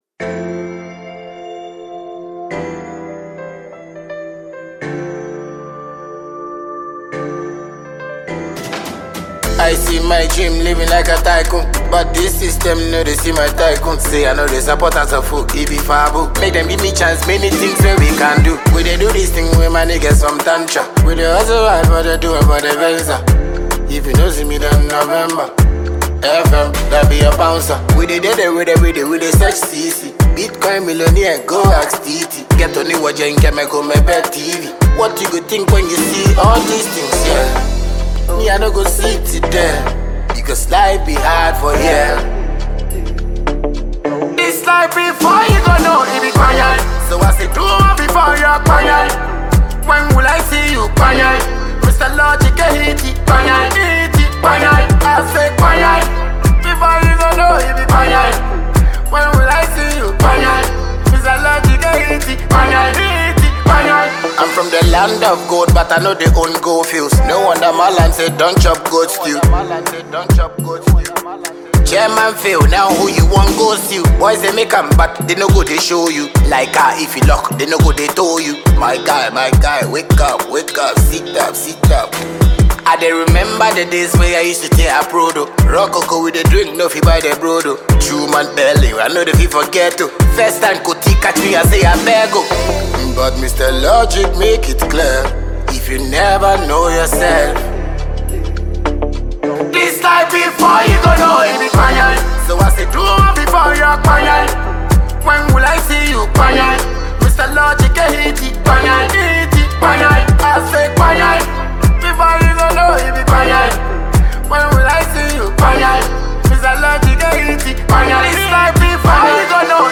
Ghanaian dancehall king